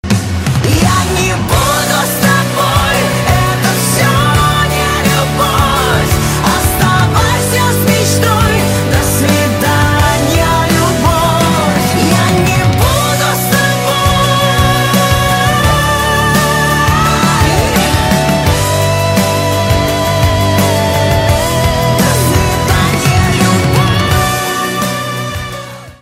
• Качество: 320, Stereo
поп
громкие
женский вокал
грустные
Pop Rock
сильные